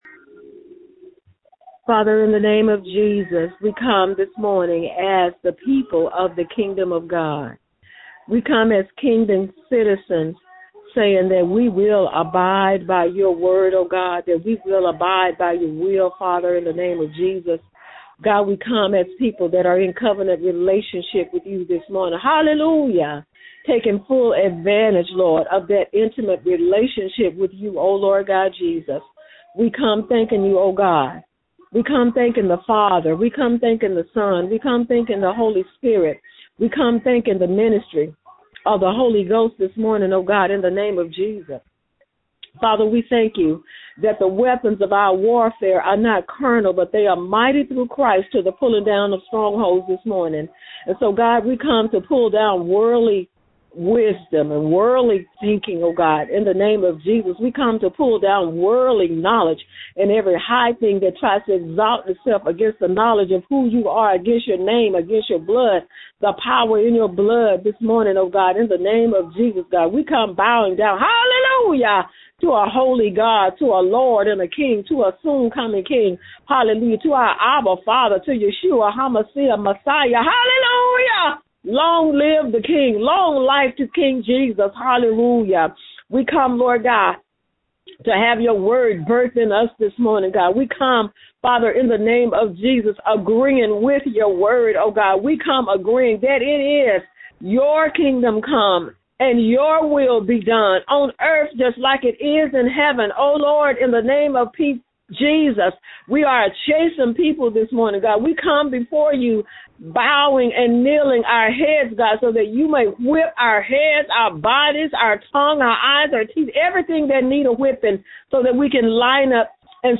This prayer prayed in the year 2022